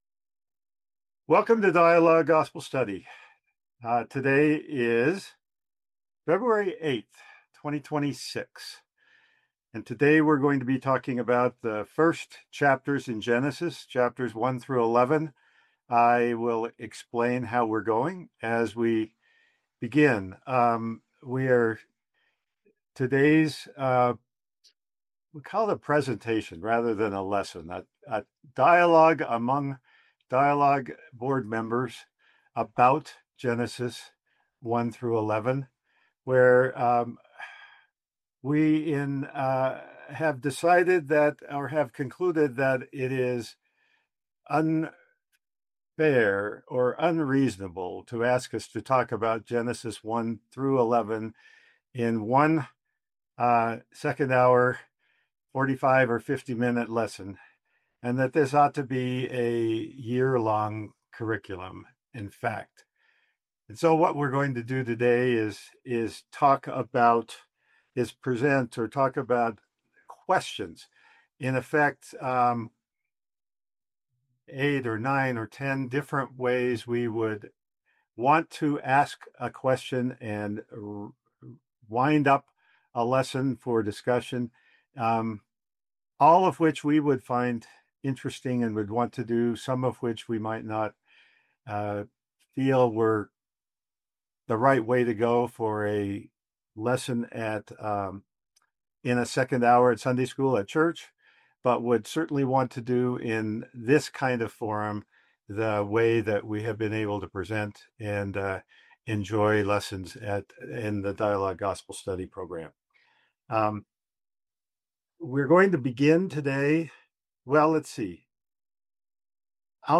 Come enjoy a thoughtful brainstorming session studying Genesis 1-11 (creation, Adam and Eve, Noah, Babel) and the relevant parts of Moses in the Pearl of Great Price with a roundtable of Dialogue board members.